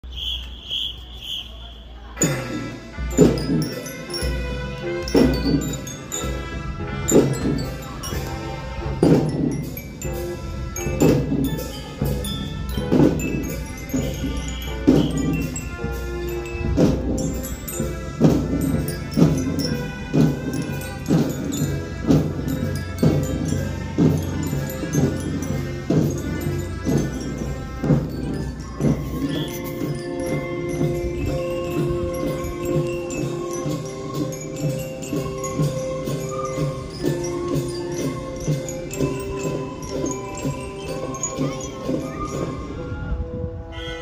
1017 ♪鼓笛♪ 群青30秒ほど
今回鼓笛でその曲を演奏しています。